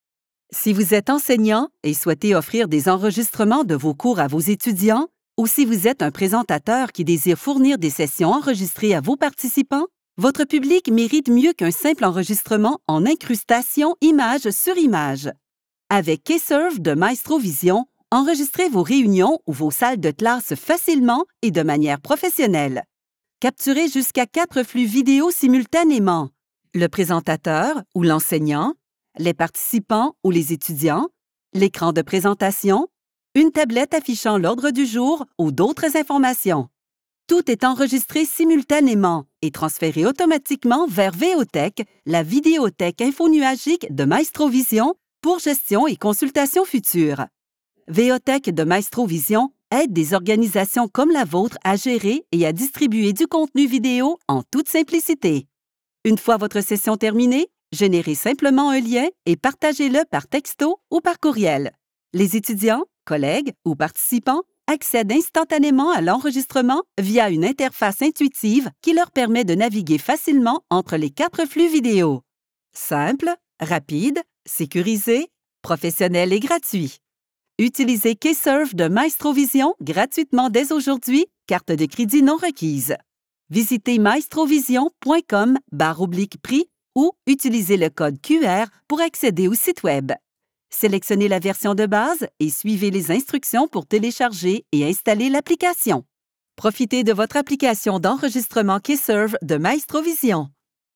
Accessible, Reliable, Corporate
Corporate